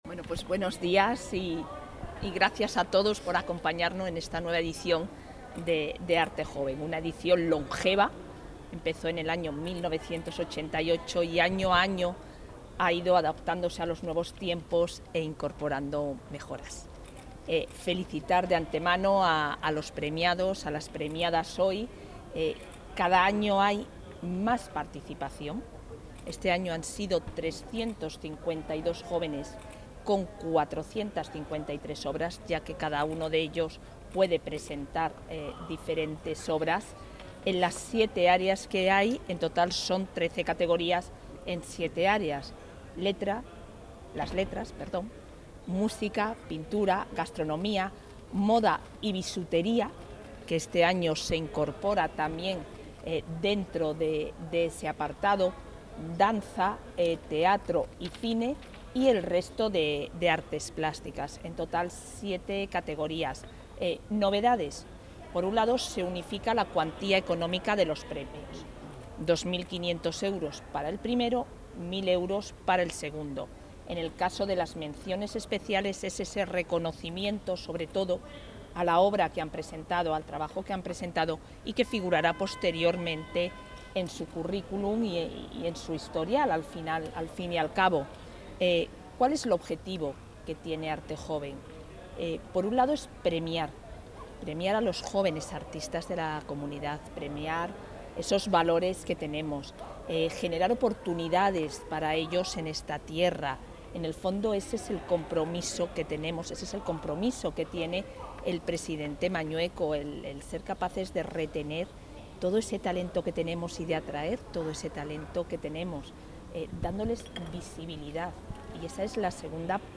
Declaraciones de la vicepresidenta de la Junta.
Entrega de premios del programa Arte Joven